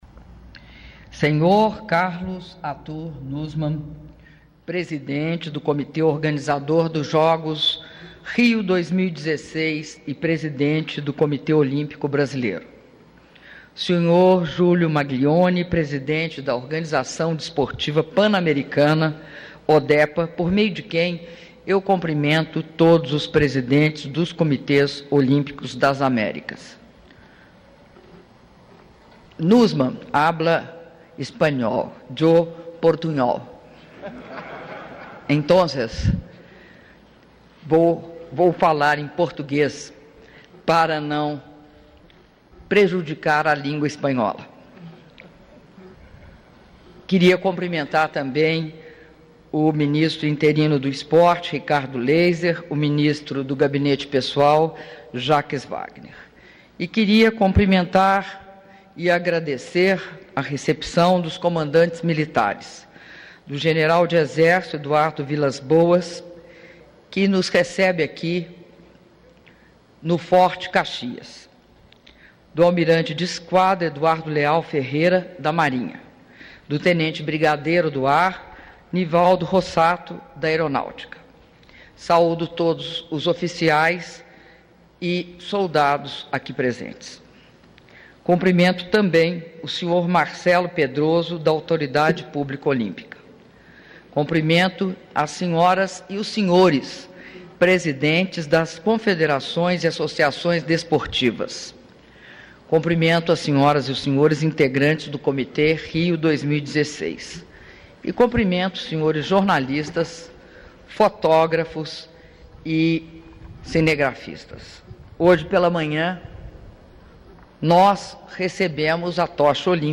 Áudio do Discurso da Presidenta da República, Dilma Rousseff, durante cerimônia da União das Américas por ocasião da chegada da Chama Olímpica Rio 2016 em Brasília - Brasília/DF (06min59s)